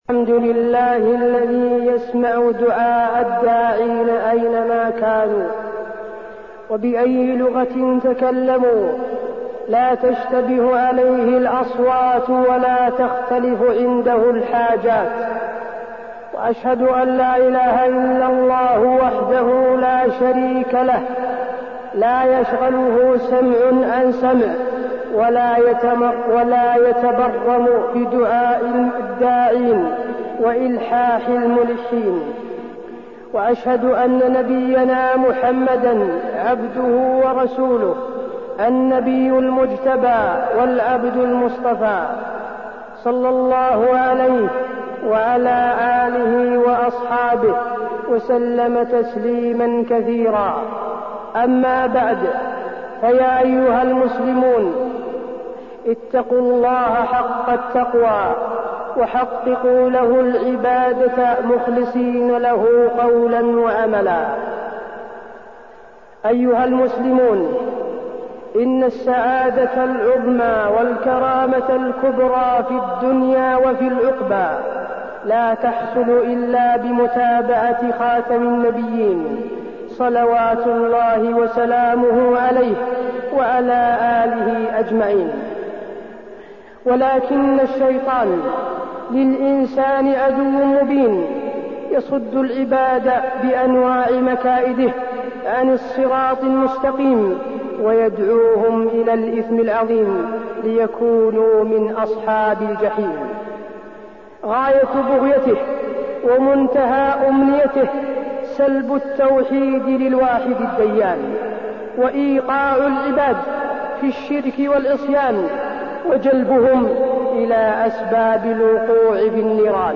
تاريخ النشر ١٠ ذو القعدة ١٤١٩ هـ المكان: المسجد النبوي الشيخ: فضيلة الشيخ د. حسين بن عبدالعزيز آل الشيخ فضيلة الشيخ د. حسين بن عبدالعزيز آل الشيخ أحكام زيارة القبور